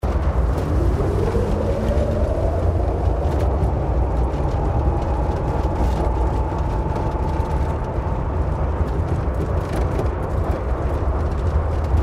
som-de-tempestade.MP3.mp3